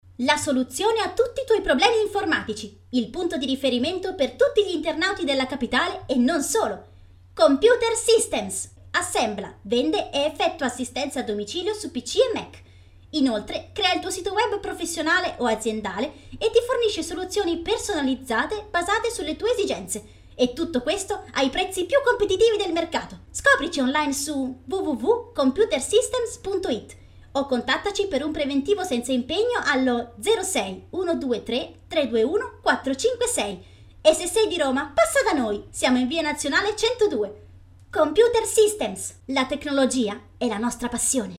Speaker italiana con voce chiara e giovanile per spot radio/tv, doppiaggio di cartoni animati e ebook.
Sprechprobe: Werbung (Muttersprache):
Italian speaker with young age voice for spot radio/tv, dubbing and voice character for cartoons.